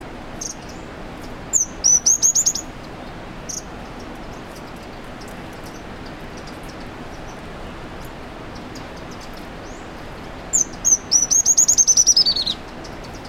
Yellow-throated Fulvetta
VOICE Song high-pitched, starting slowly then building speed and ending in a slurred jumble. Twittering call, chit.
fulvetta-yellow-throated002-Alcippe-cinerea.mp3